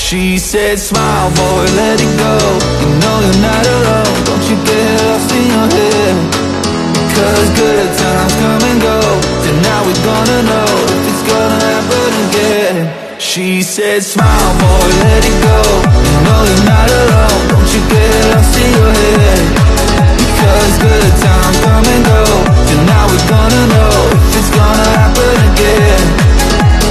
Class 66 60mph GBRF Railfreight sound effects free download
Diesel-hauled engineering train passing through ponty-y-clun with tones